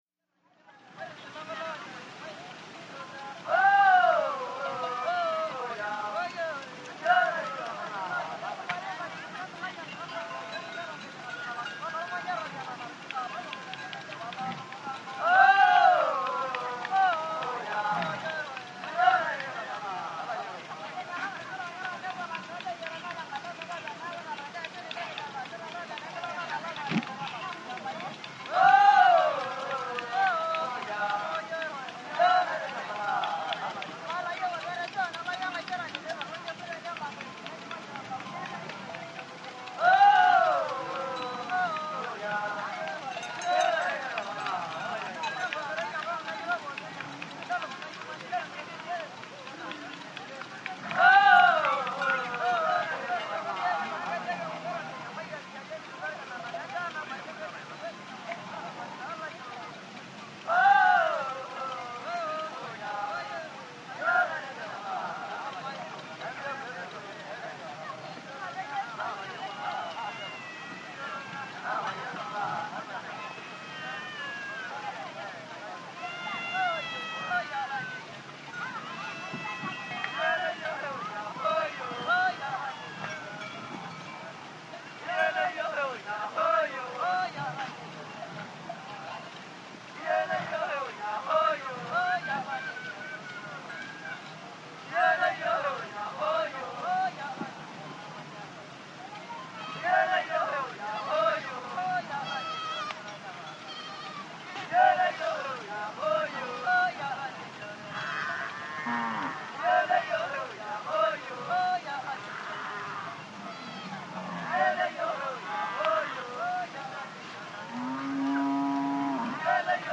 CROWD - FOREIGN AFRICA: MASAI: Cattle bells, herd passes by, wind background. Masai settlement.